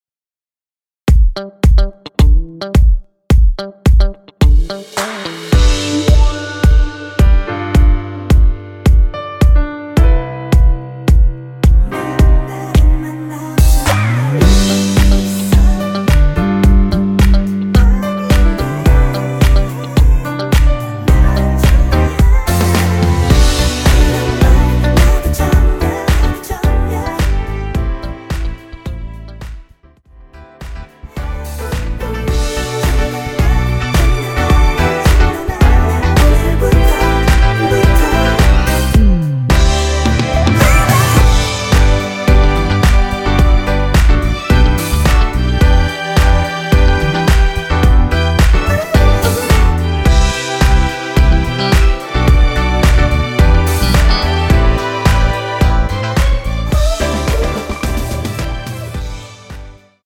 원키에서(+3)올린 코러스 포함된 MR입니다.(미리듣기 확인)
앞부분30초, 뒷부분30초씩 편집해서 올려 드리고 있습니다.